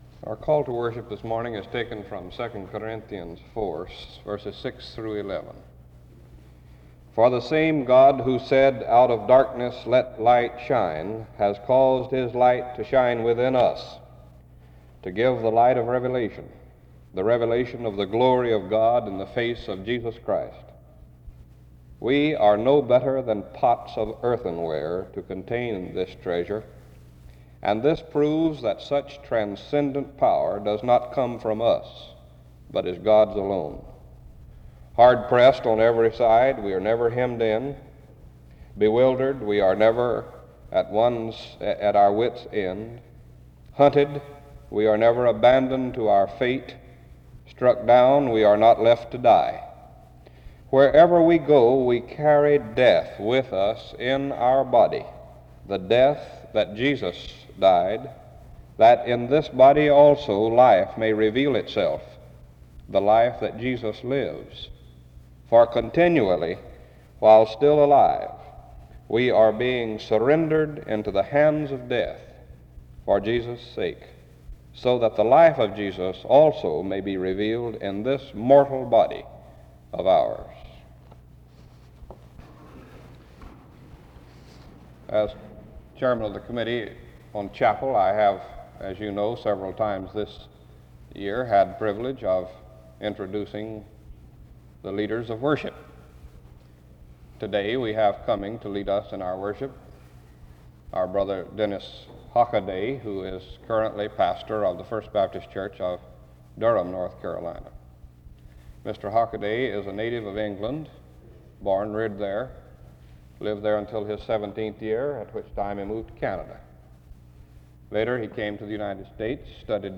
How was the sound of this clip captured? The chapel service opens up with the scripture reading of 2 Corinthians 4:6-11.